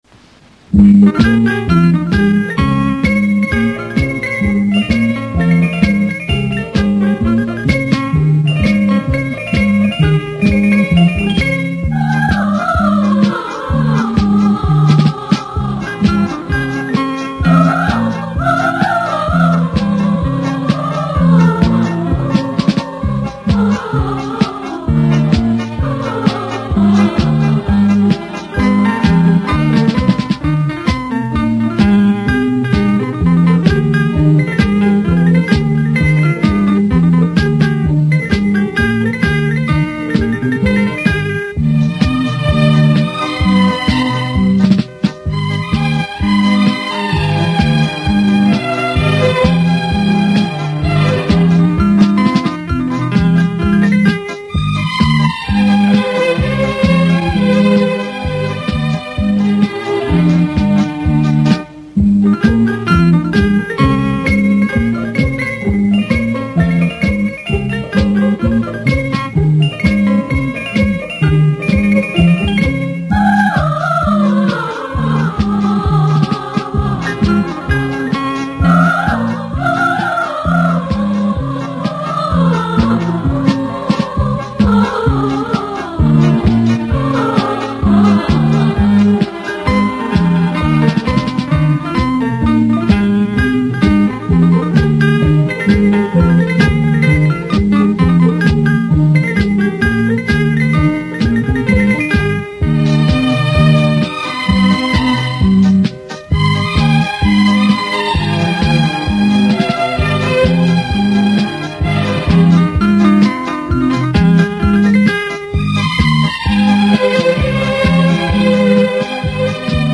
[21/5/2010]求助几首轻音乐的名字（有试听）